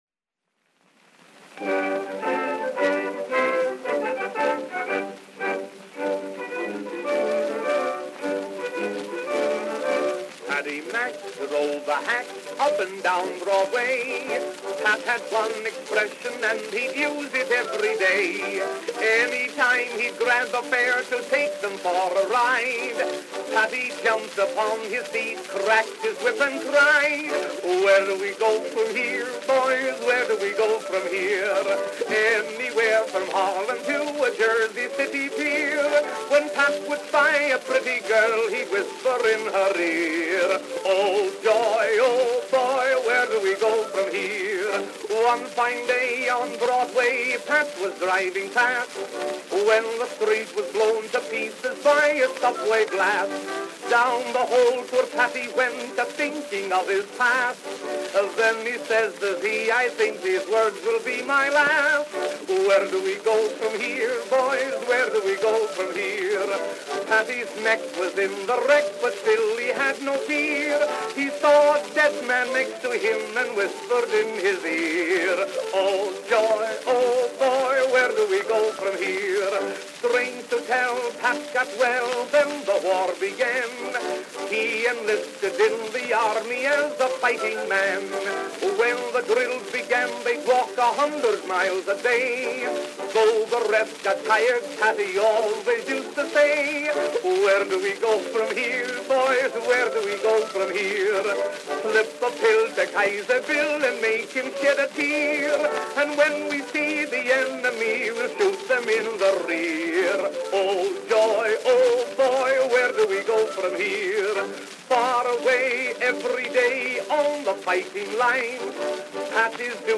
Our collection is comprised of wax cylinder donations from many sources, and with the digitization process being fully automated, not all listed contents have been confirmed.